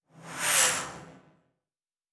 Sci-Fi Sounds / Movement / Fly By 07_4.wav
Fly By 07_4.wav